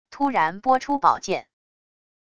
突然拨出宝剑wav音频